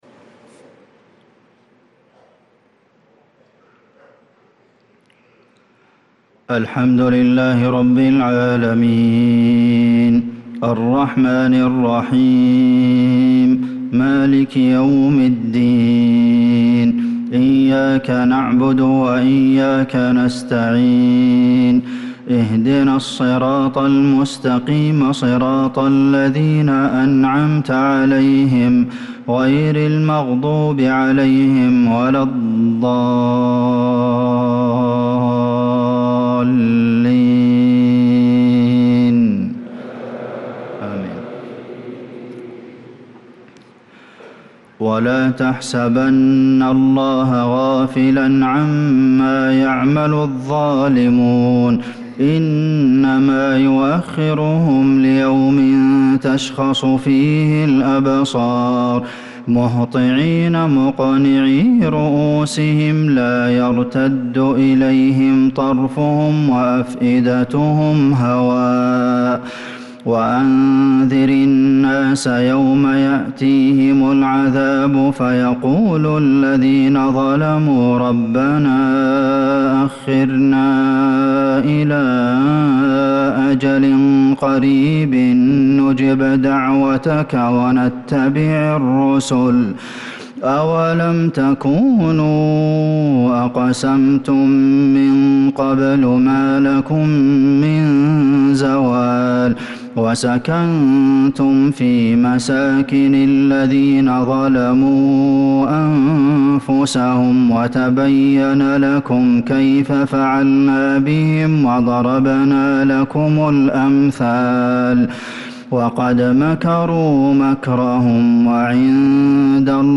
صلاة العشاء للقارئ عبدالمحسن القاسم 16 ربيع الأول 1446 هـ